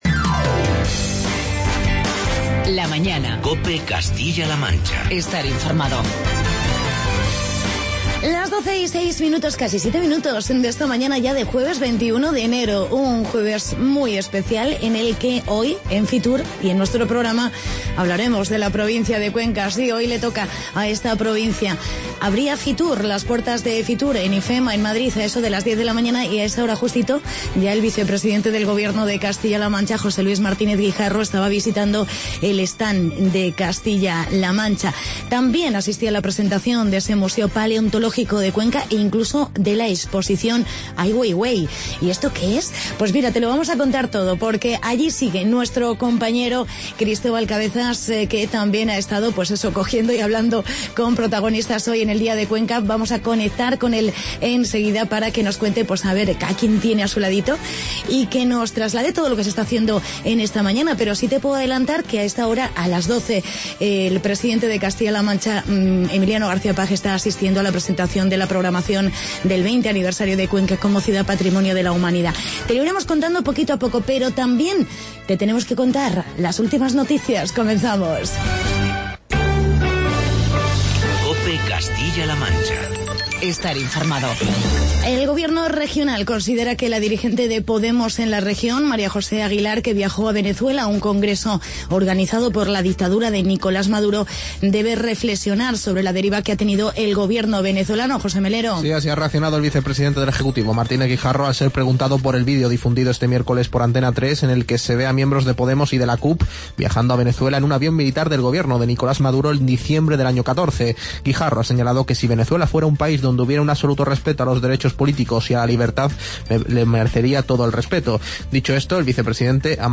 Programa especial Fitur.